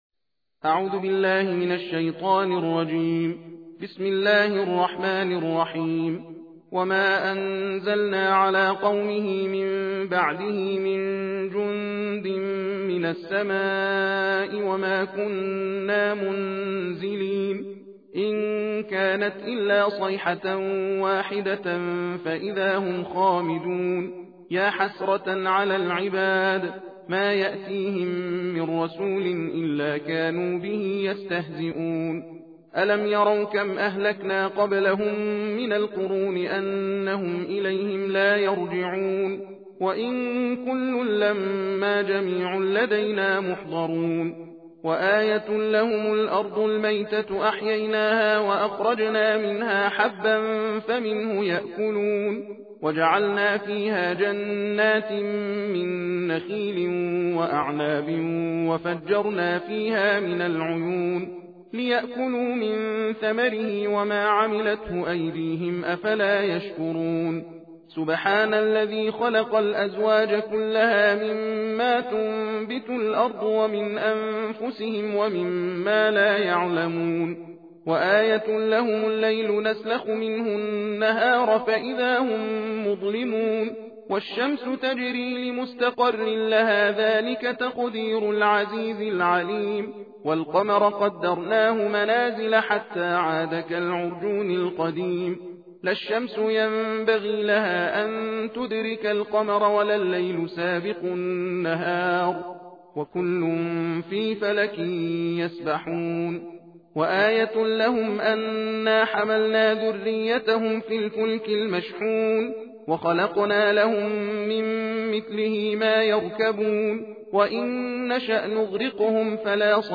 بشنوید | تلاوت جزء بیست‌وسوم قرآن کریم | قوی ترین طلسمات+قویترین دعا و طلسم+قوی ترین کتاب طلسم و دعانویسی